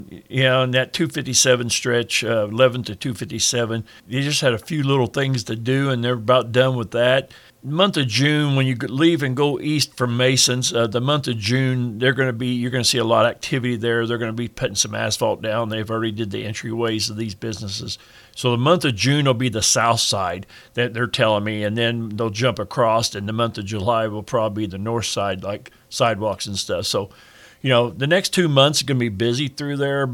Washington Mayor Dave Rhoads stopped by for a city update yesterday. The mayor touched on the busy month of June here in Washington and gave us an update on the Business 50 project…